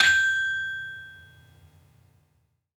Saron-4-G5-f.wav